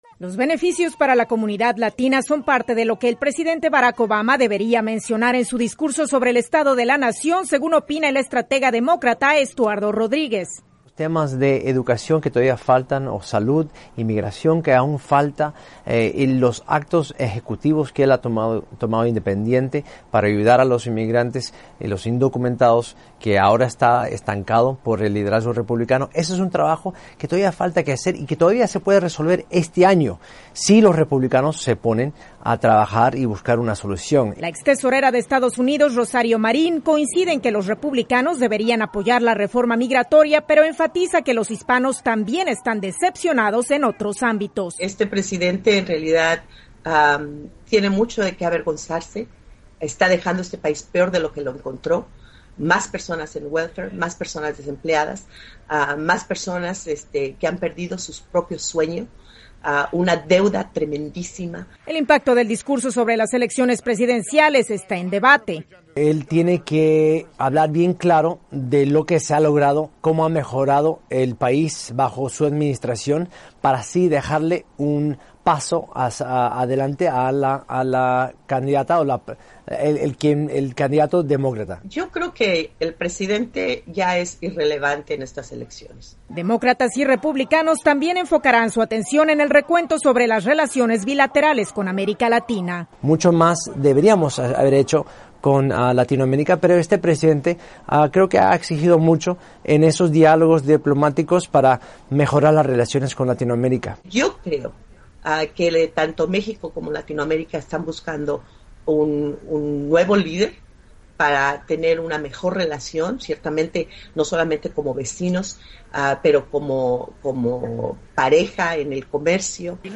informe especial